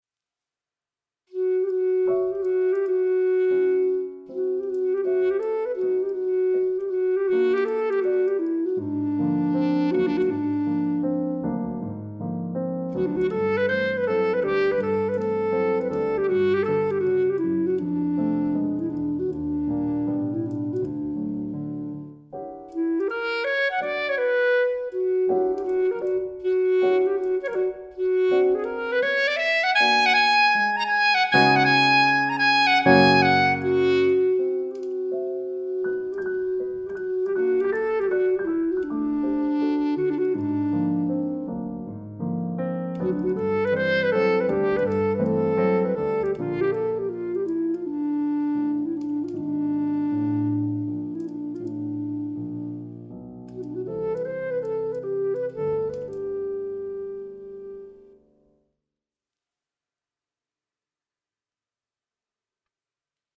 cette clarinette s'intègre aussi très bien (à mon avis) dans un contexte plus classique
Debussy-Clar-Piano.mp3